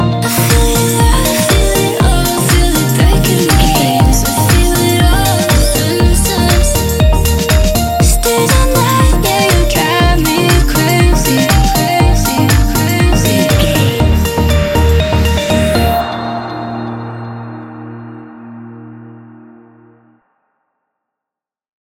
Ionian/Major
D♯
house
electro dance
synths
techno
trance